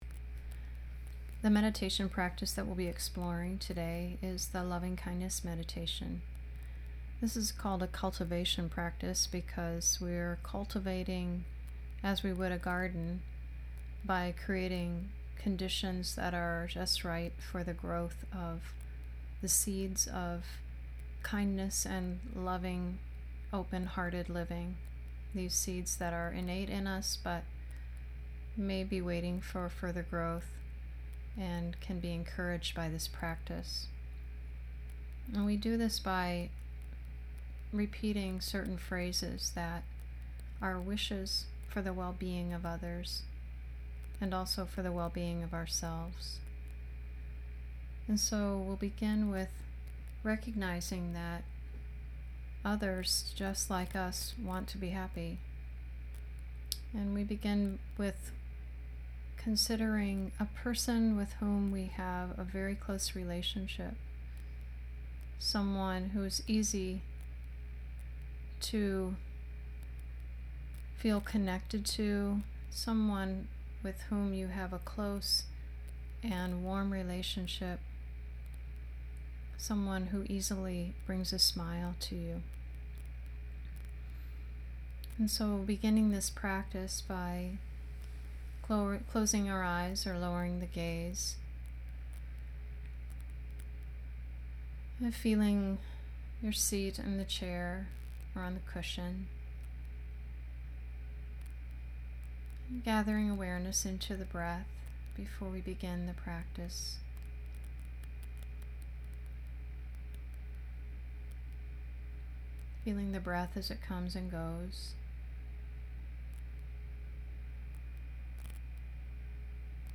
Loving-Kindness Meditation - 25 minutes